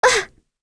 Cleo-Vox_Damage_01.wav